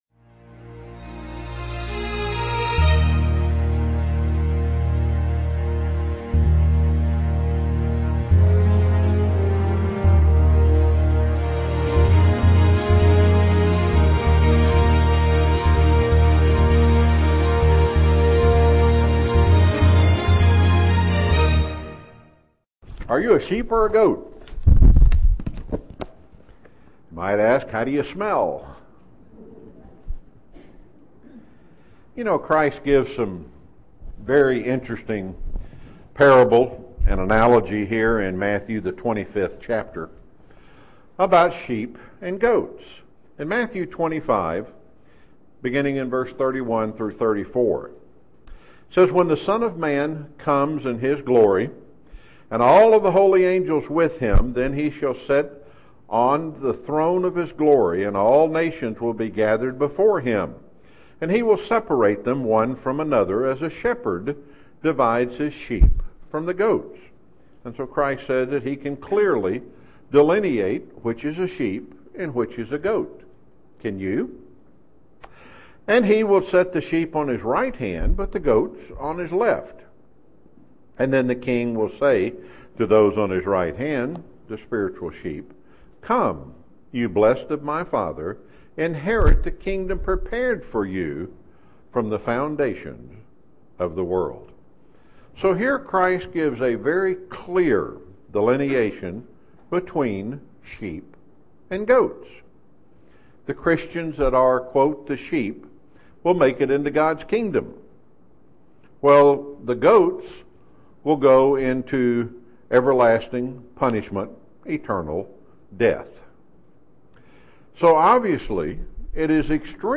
UCG Sermon Studying the bible?
Given in Chattanooga, TN